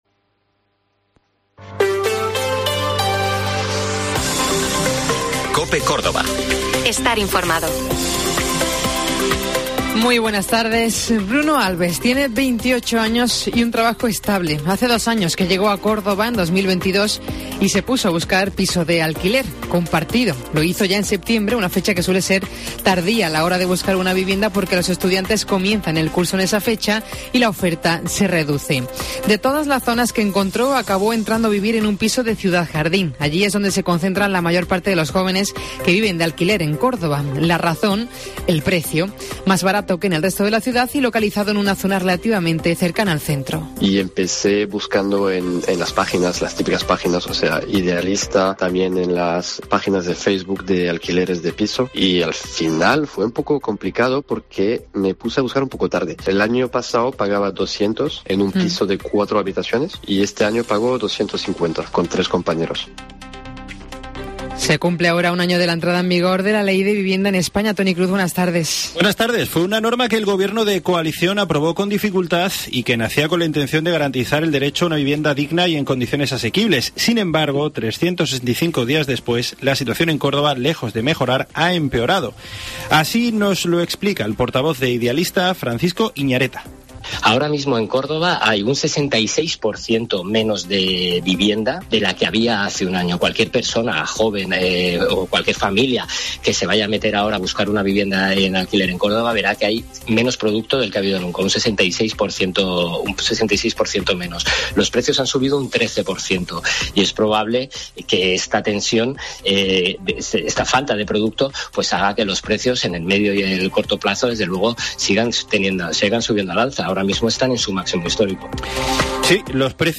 Reportaje vivienda